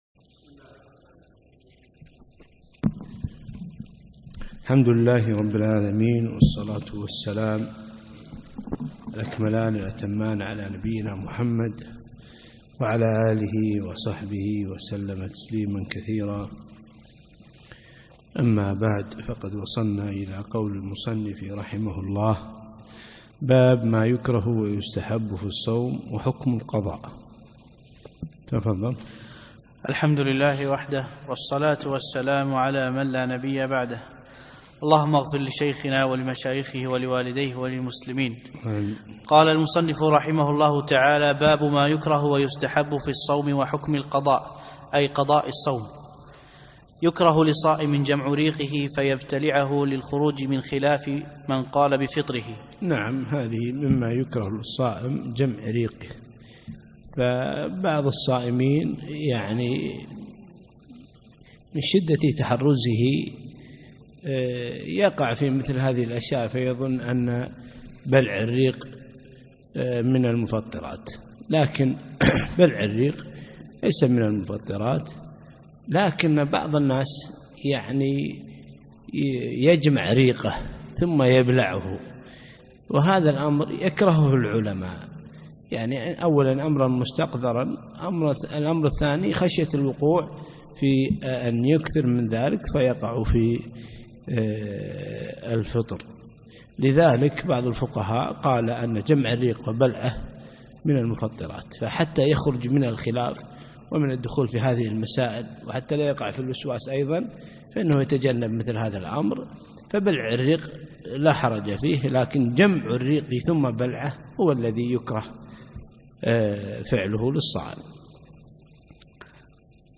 الدرس الرابع